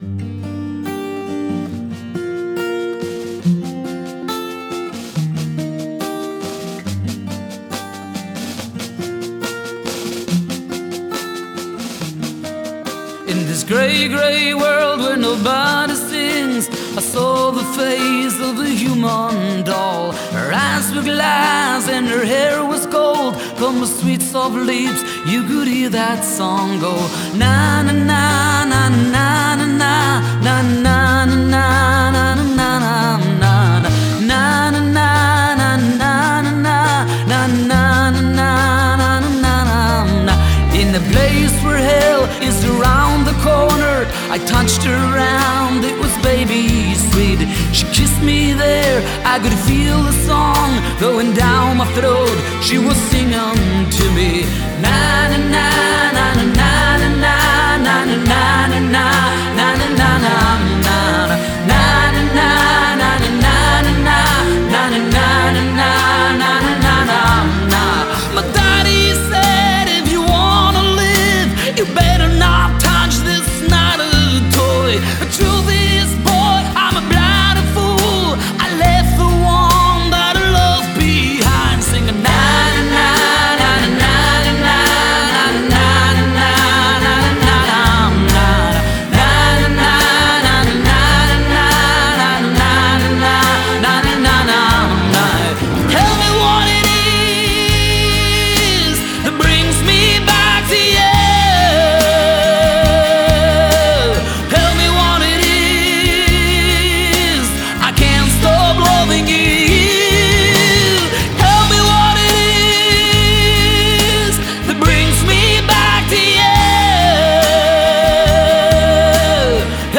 европейская фолк/рок группа